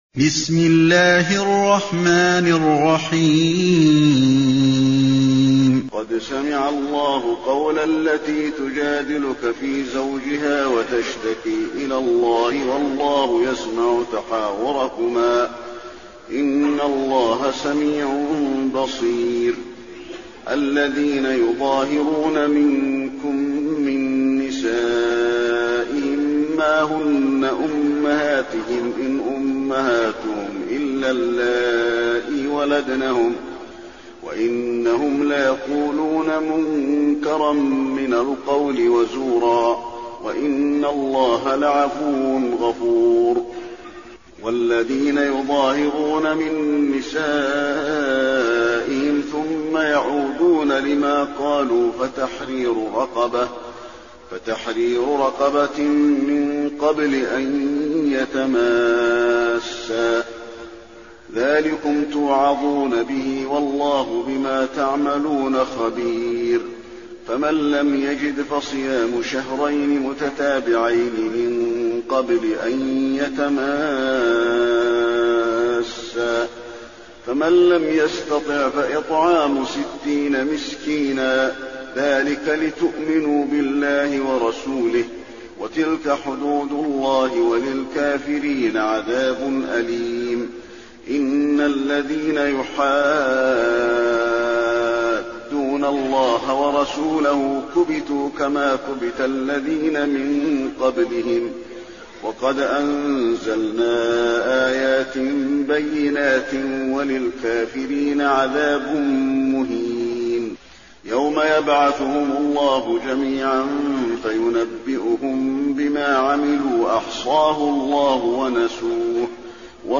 المكان: المسجد النبوي المجادلة